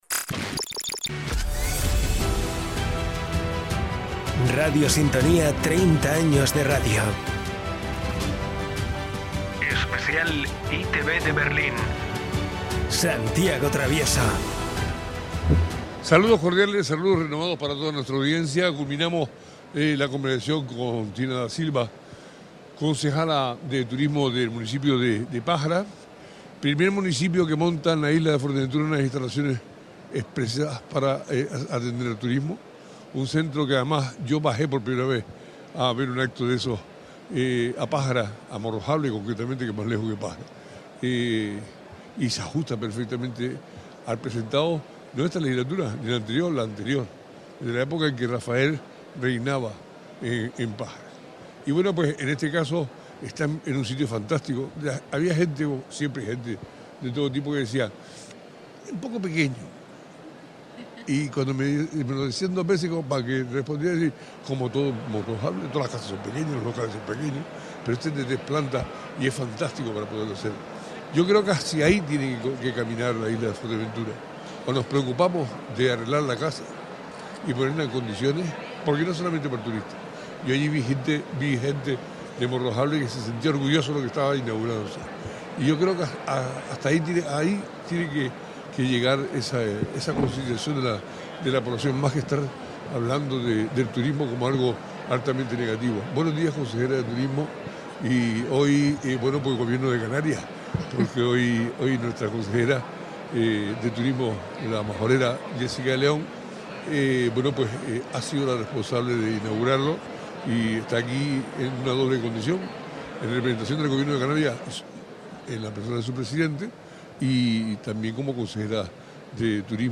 La Consejería de Turismo del Gobierno de Canarias cuenta con un presupuesto por valor de 30 millones de euros destinado a la Estrategia de Renovación de Espacios Turísticos vinculados a la actividad turística, según recordó hoy en la radio la consejera de Turismo y Empleo del Gobierno de Canarias, Jéssica de León quien precisó que […]